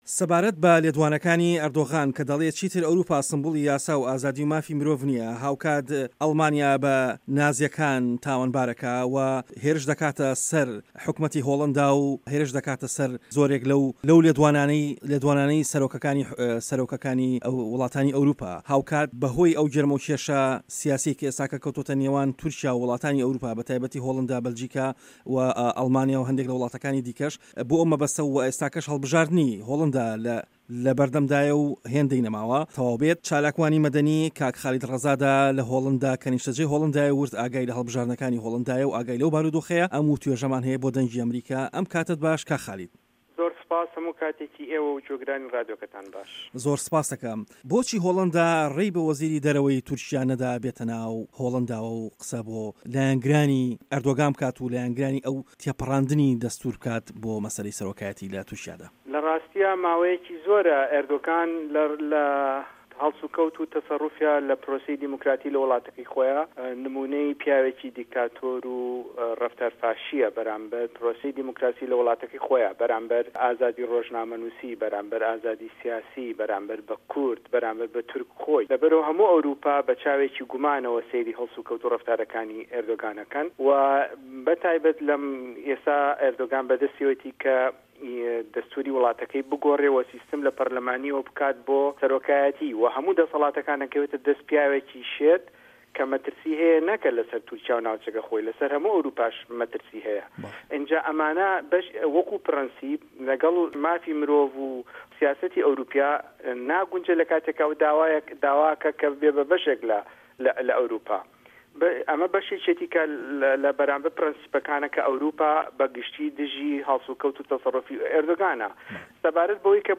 جیهان - گفتوگۆکان